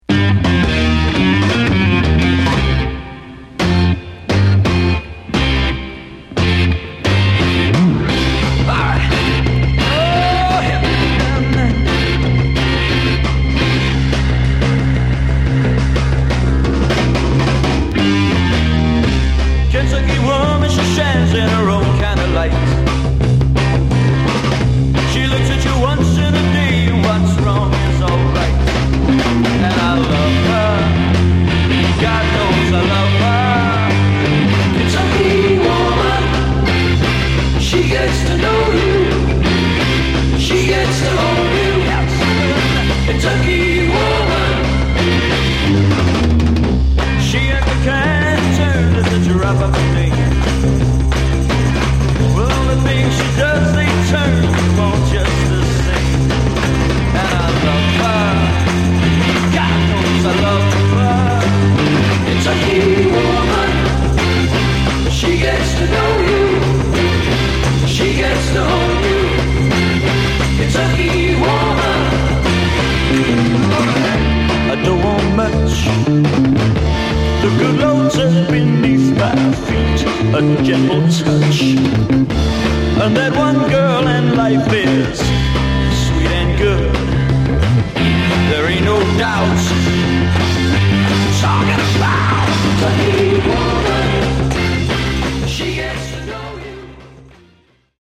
Genre: Garage/Psych
psych-rock cover